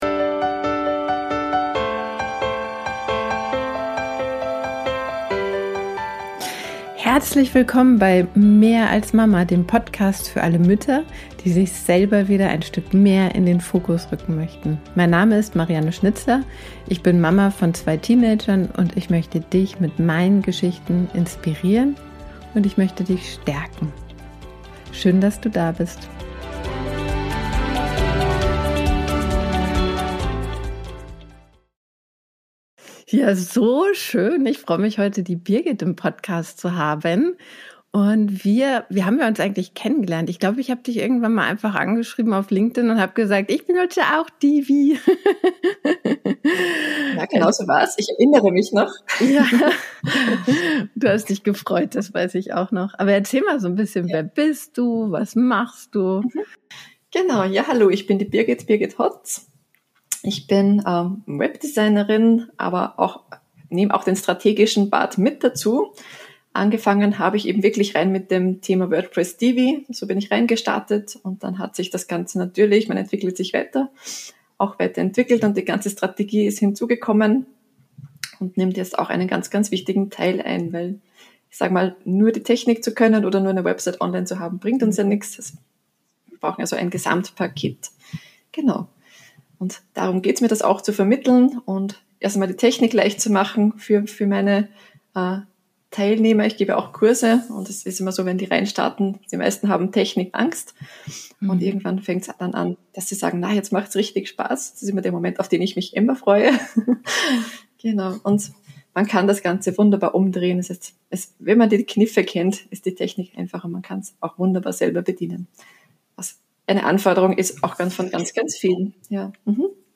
Wir erkunden, wie sie zum Webdesign kam und ein nachhaltiges Business mit Fokus auf SEO und Kundenautonomie aufbaute. Dieses Gespräch ist eine Einladung an alle, die den Sprung in die Selbstständigkeit erwägen, aber zögern, ihre Stimme zu erheben.